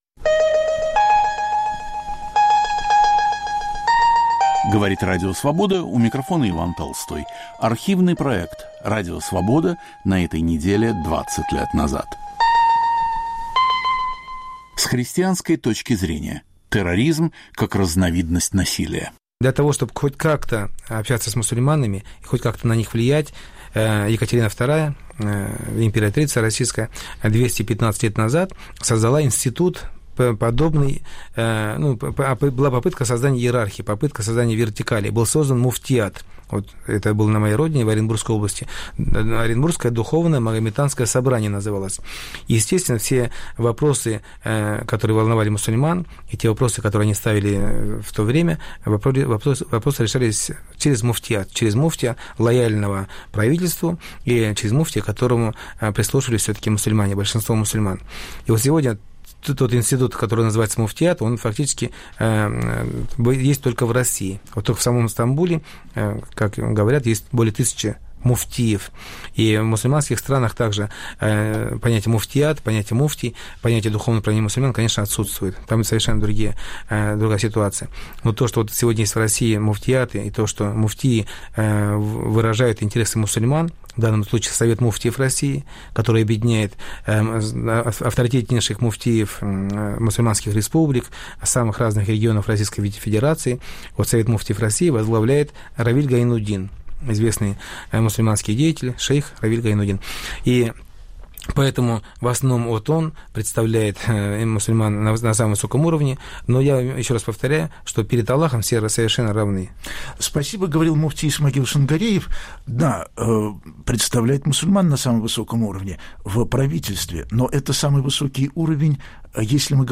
Об отношении христианства к терроризму, прежде всего, к исламскому. В передаче участвуют мусульмане и христиане.